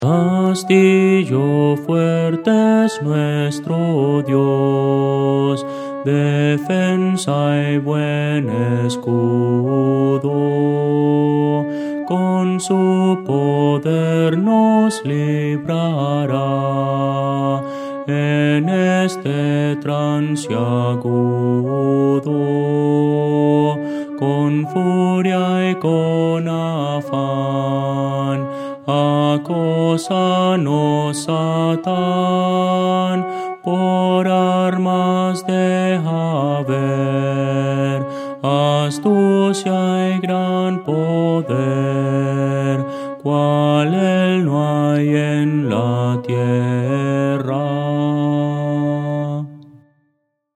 Voces para coro
Contralto – Descargar
Audio: MIDI